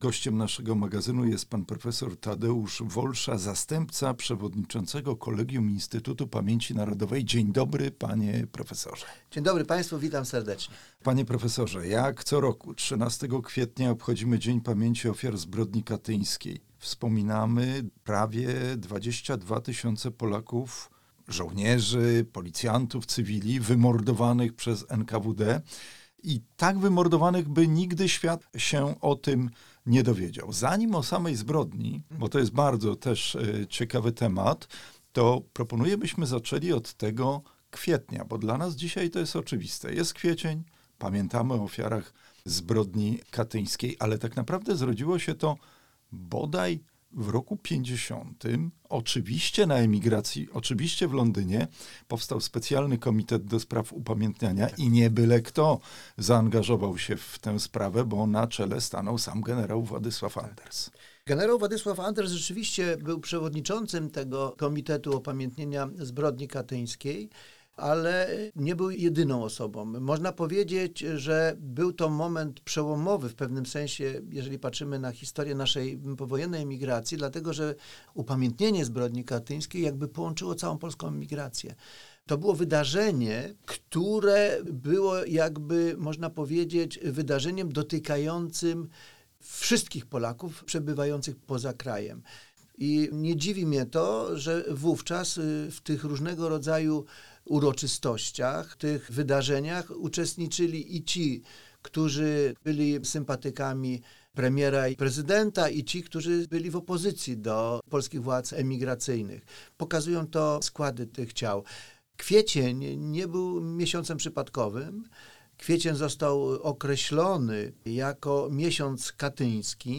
Dzień Pamięci Ofiar Zbrodni Katyńskiej. Rozmowa